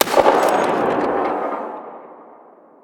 AntiMaterialRifle_far_01.wav